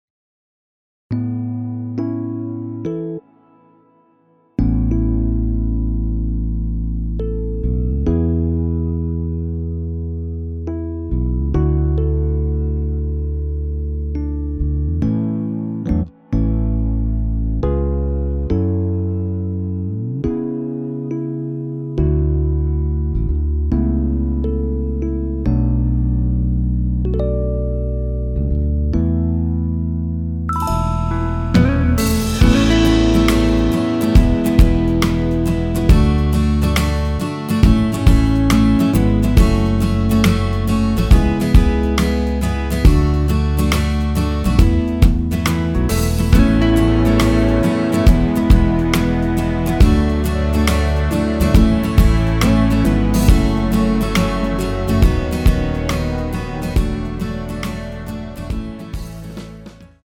전주 없이 시작 하는 곡이라 전주 1마디 만들어 놓았습니다.(미리듣기 참조)
원키에서(-1)내린 MR입니다.
앞부분30초, 뒷부분30초씩 편집해서 올려 드리고 있습니다.